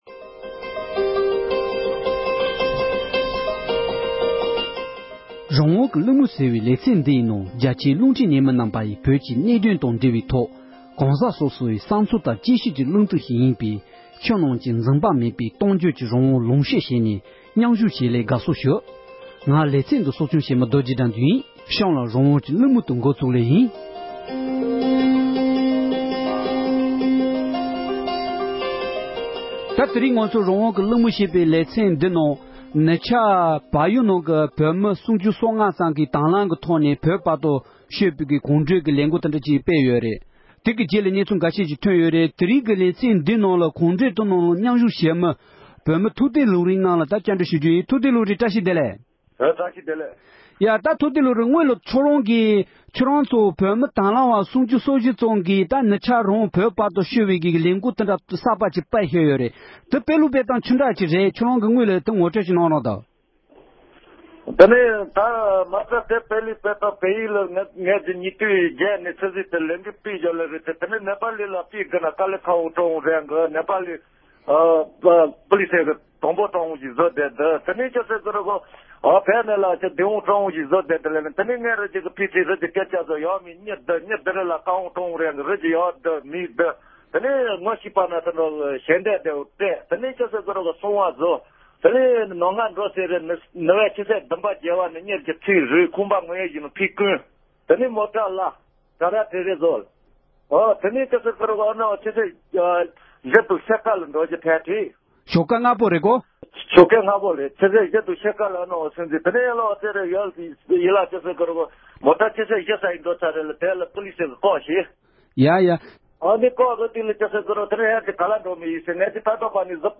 གོང་གི་ལས་འགུལ་ནང་མཉམ་ཞུགས་གནང་མྱོང་བའི་བོད་མི་ཞིག་ལ་འབྲེལ་ཡོད་གནས་ཚུལ་ཁག་བཀའ་འདྲི་ཞུས་པ་ཞིག